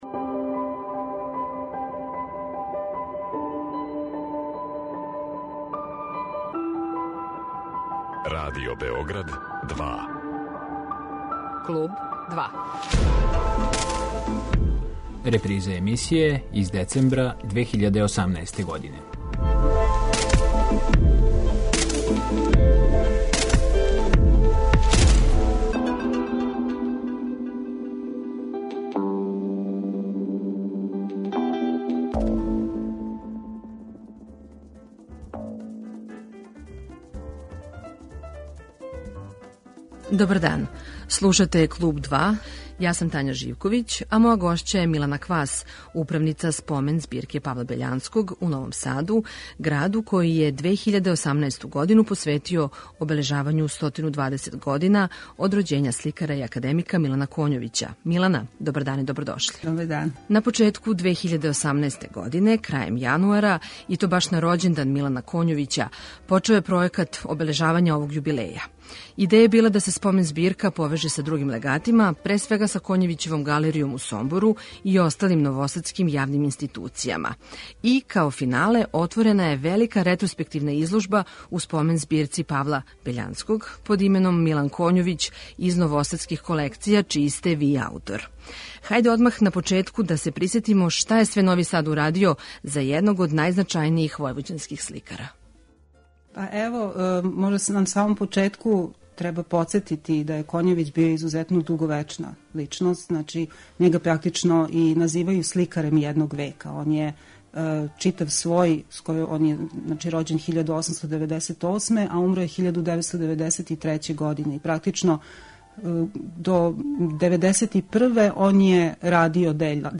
Емитујемо разговор